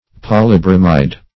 Search Result for " polybromide" : The Collaborative International Dictionary of English v.0.48: Polybromide \Pol`y*bro"mide\, n. [Poly- + bromide.]